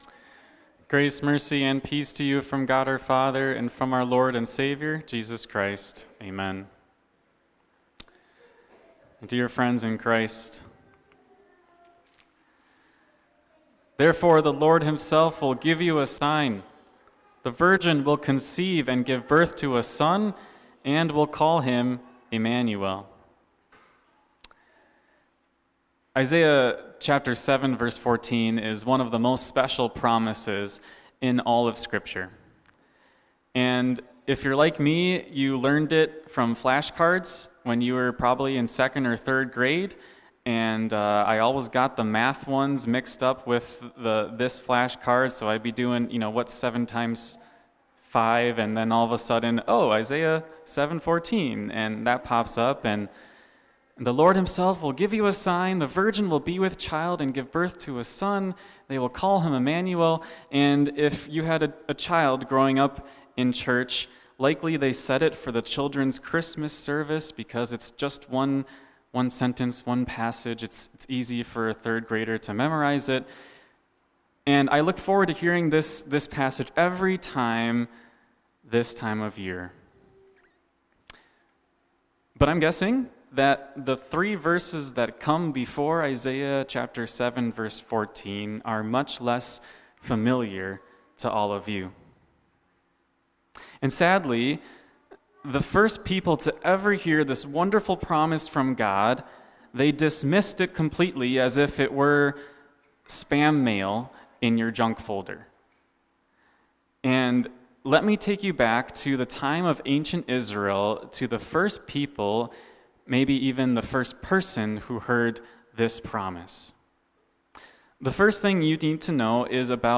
Bulletin / Youtube - Sunday Bulletin, Recorded Sermon Audio, And Youtube Link